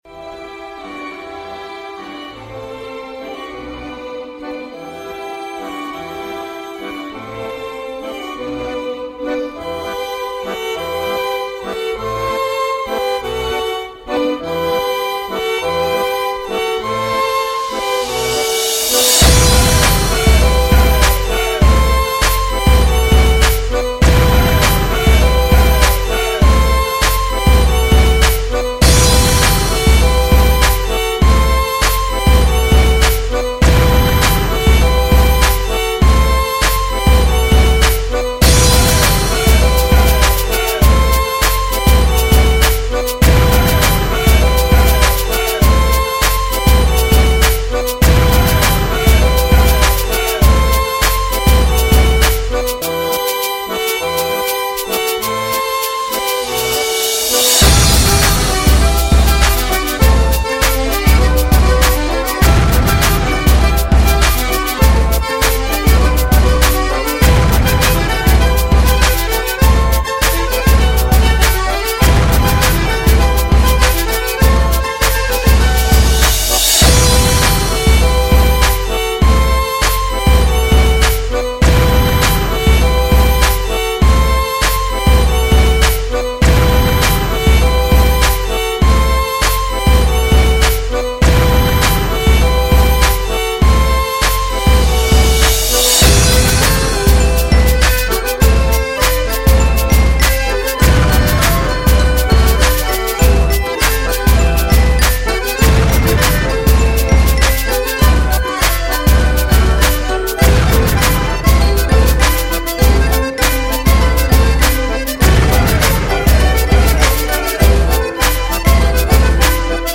__Kf__quot_SHag_Vpered_quot____Skripka_i_basy.mp3